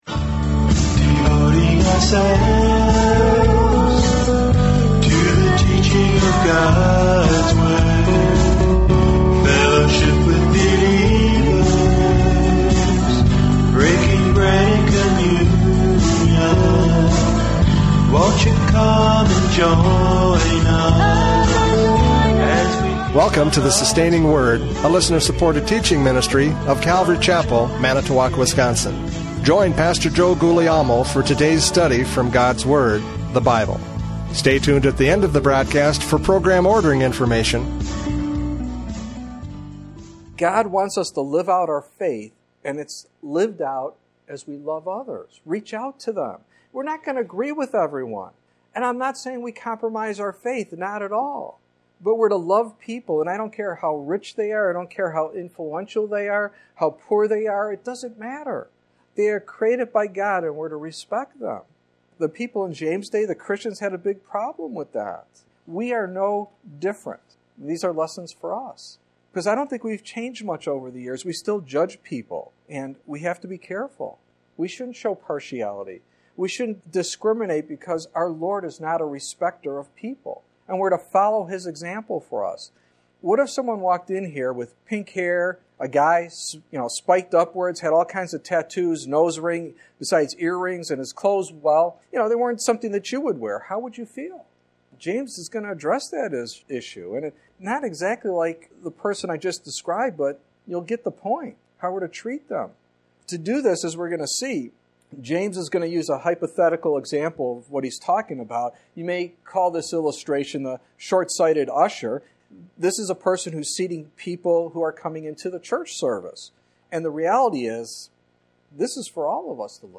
James 2:1-13 Service Type: Radio Programs « James 2:1-13 Faith is Proved by Love!